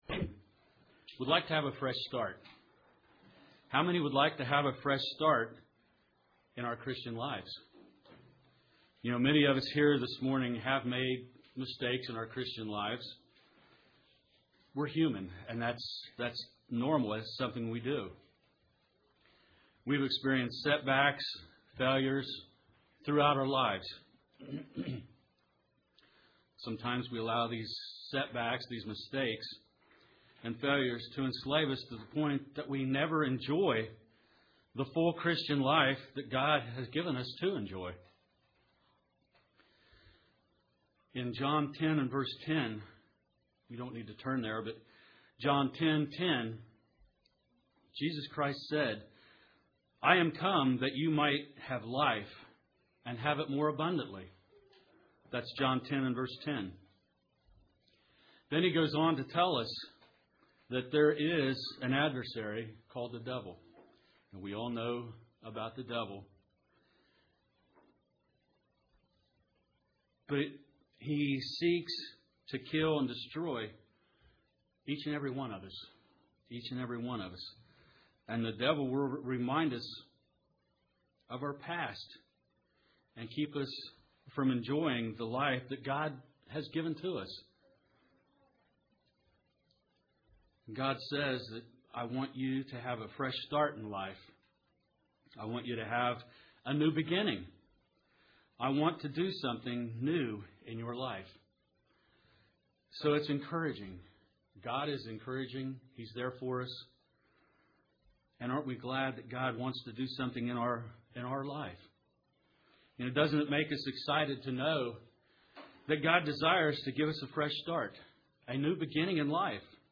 Re-start when things go wrong, In this sermon , it is brought to our attention the blessing God gives us which enables us to re-start our lives . No matter how many times it takes , God is there guiding us, directing us to start over.
Given in Paintsville, KY